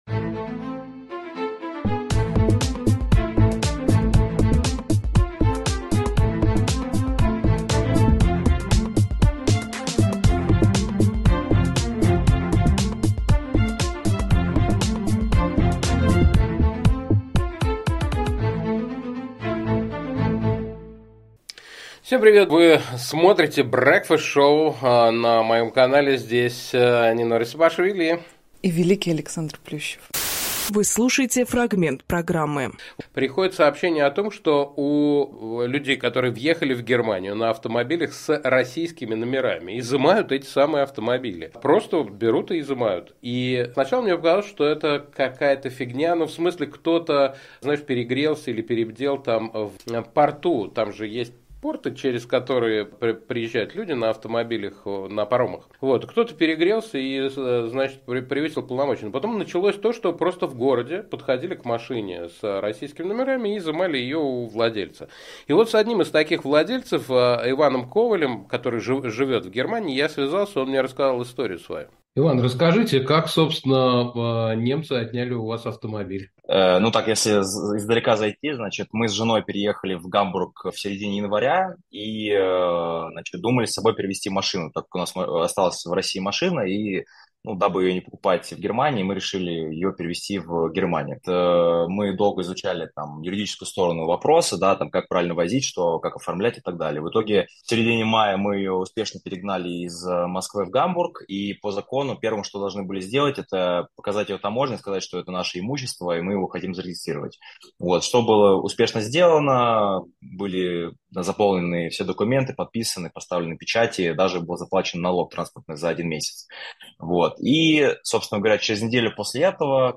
Фрагмент утреннего эфира.